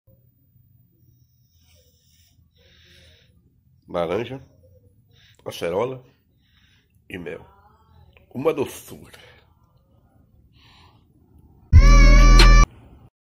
Guy Drinking Orange Juice